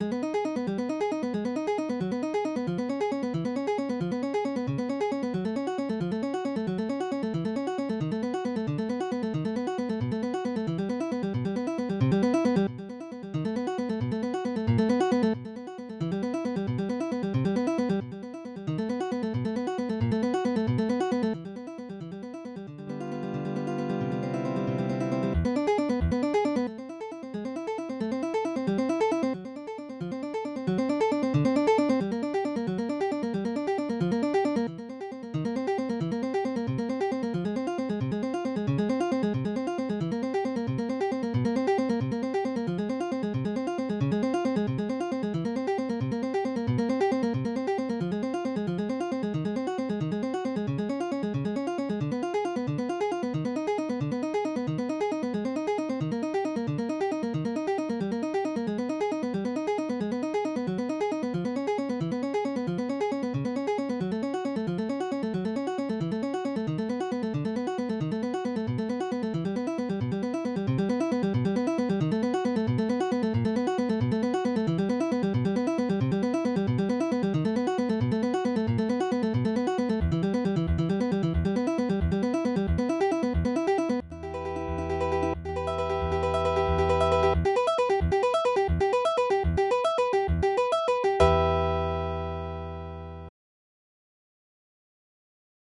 Midi音楽が聴けます 3 170円